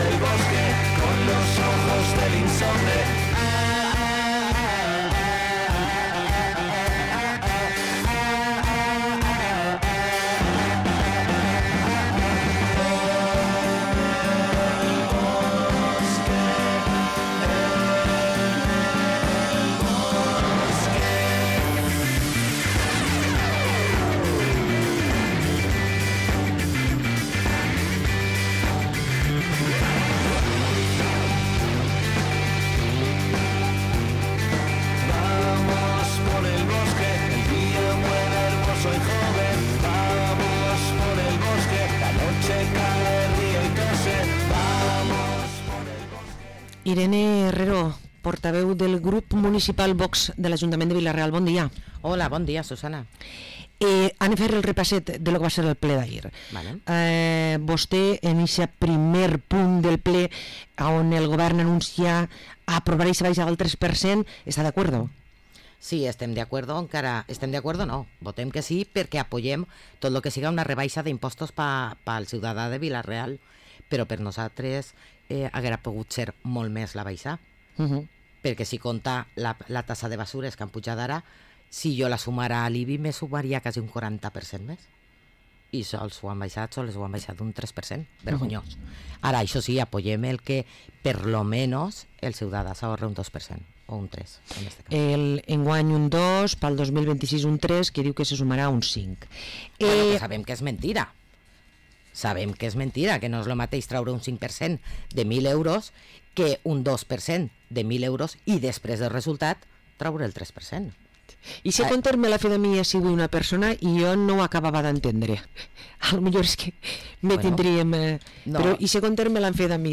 Parlem amb Irene Herrero, portaveu de VOX a l´Ajuntament de Vila-real
26-09-25-irene-herrero-valoracio-ple-ordinari.mp3